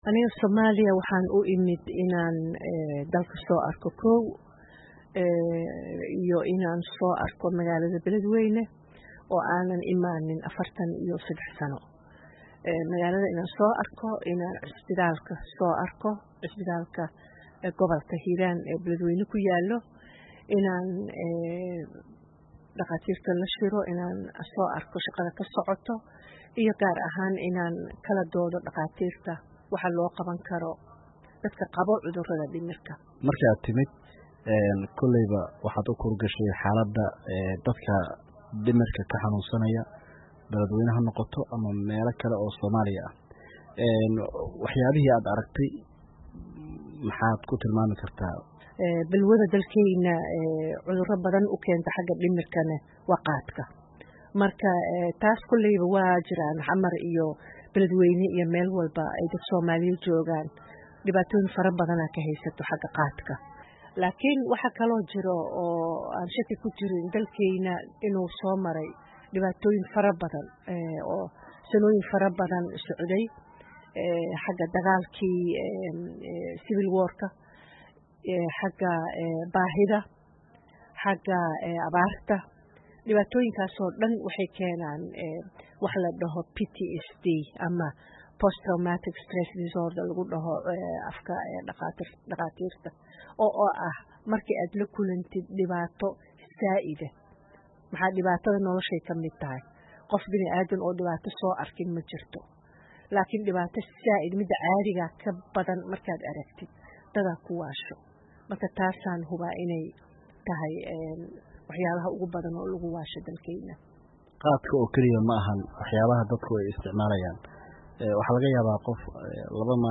Wareysi: Xaaladda Cudurrada Dhimirka ee Soomaaliya